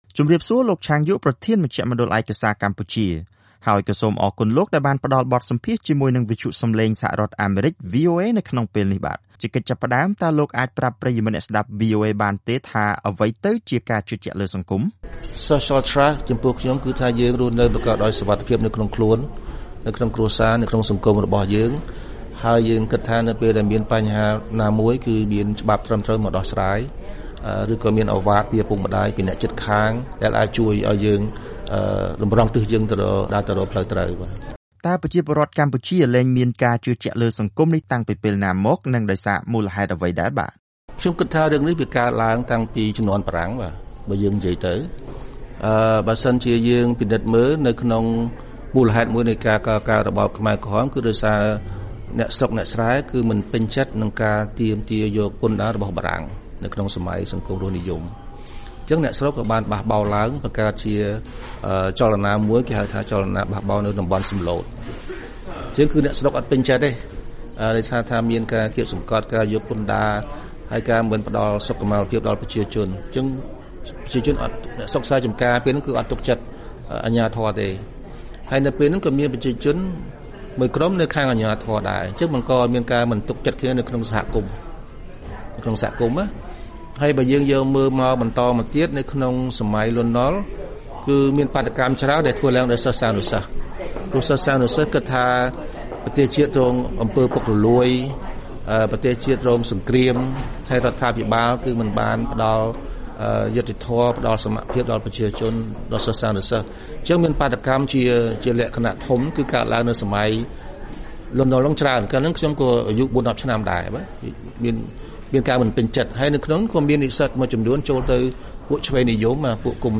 បទសម្ភាសន៍ VOA៖ ប្រជាពលរដ្ឋខ្មែរជឿជាក់លើខ្លួនឯងច្រើនជាងជឿជាក់លើសង្គម